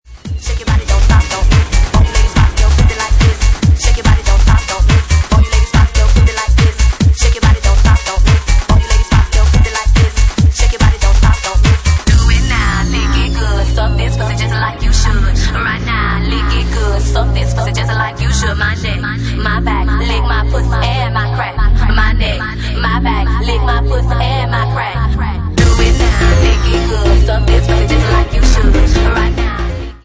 Genre:Bassline House
Bassline House at 143 bpm